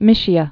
(mĭshē-ə)